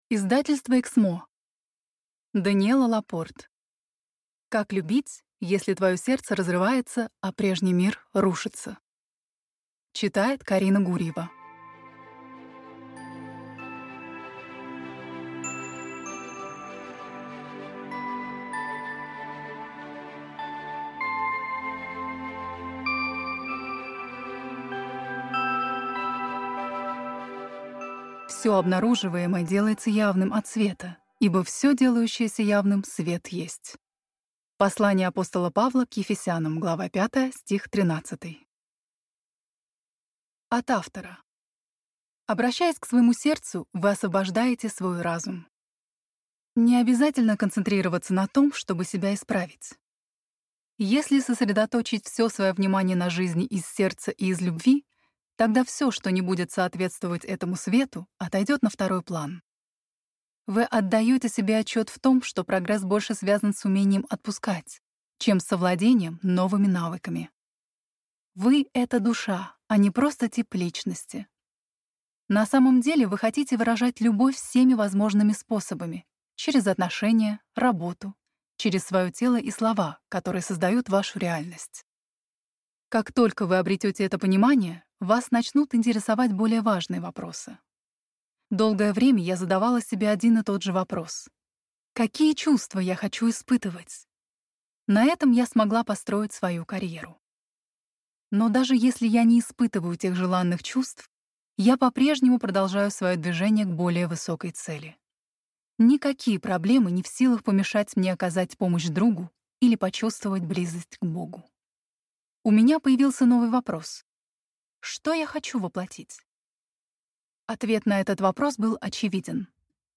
Аудиокнига Как любить? Если твое сердце разрывается, а прежний мир рушится | Библиотека аудиокниг